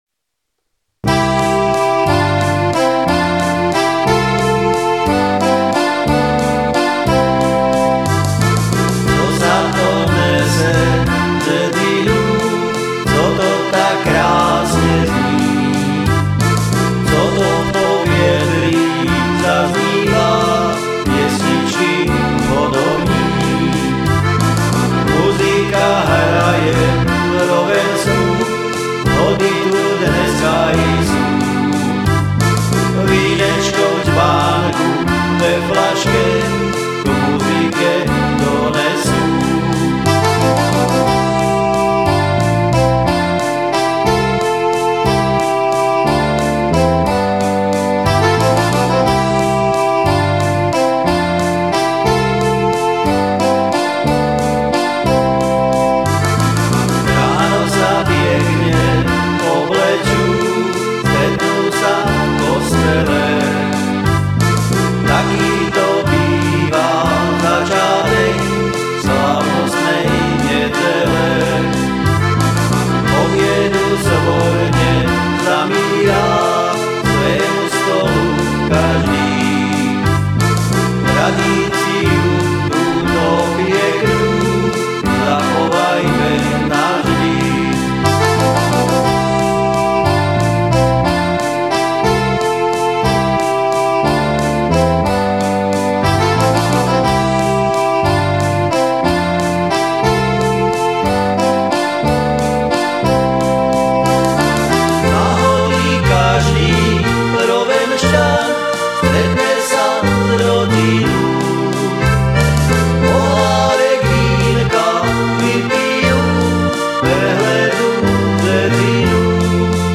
Záhorácke ľudové piesne - Piesne 1CD - Hody
Som amatérsky muzikant, skladám piesne väčšinou v "záhoráčtine" a tu ich budem prezentovať.